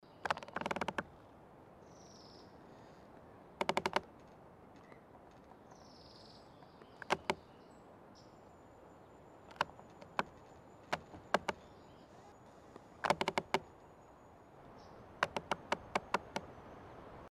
Звуки растущего дерева
На этой странице представлены редкие звуки растущего дерева — от едва уловимого шепота молодых побегов до глубоких вибраций старого ствола.